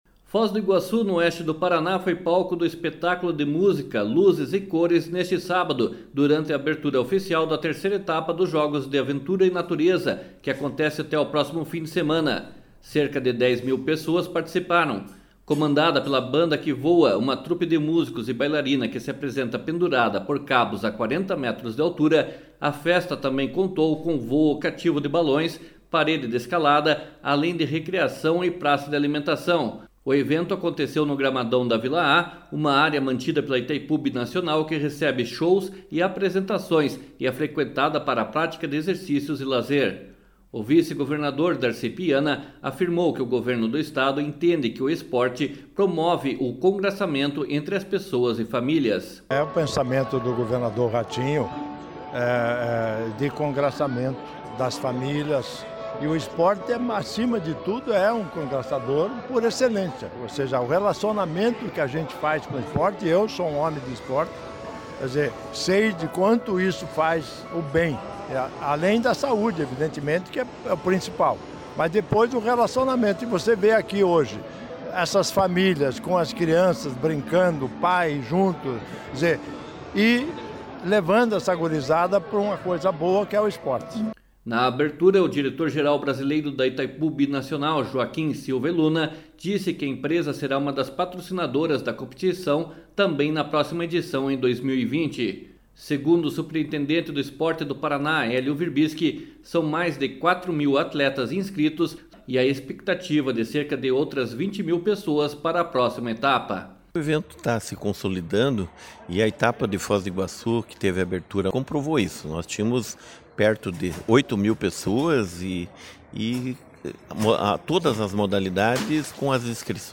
// SONORA DARCI PIANA //
// SONORA HELIO WIRBISKI //